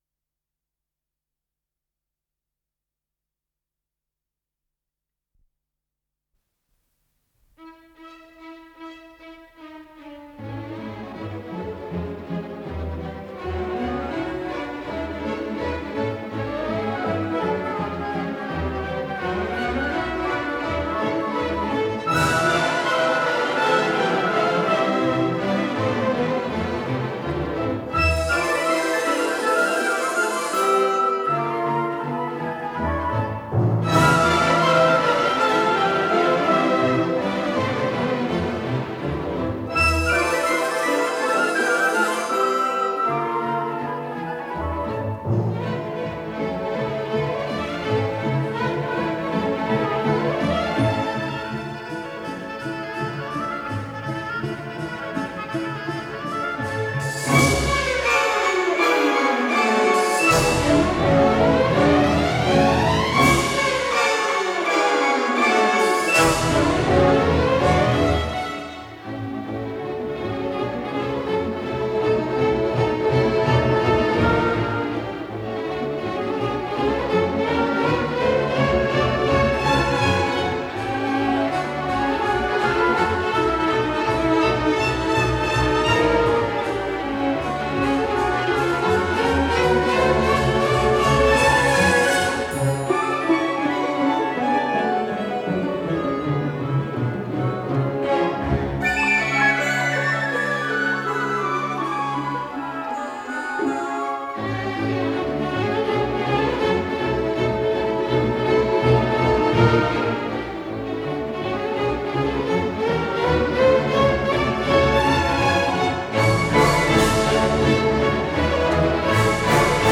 ПодзаголовокСюита по произведениям Фридерика Шопена для симфонического оркестра
Тарантелла, соч. 43, ля мажор
Дирижёр - Альгис Жюрайтис